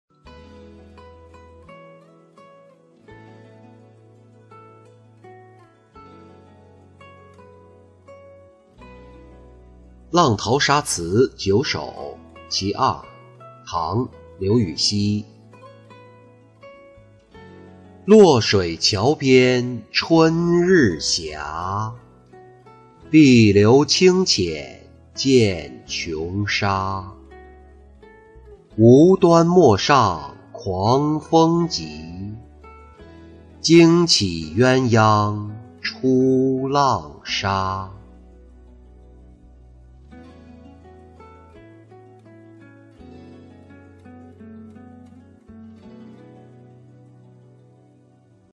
浪淘沙·其二-音频朗读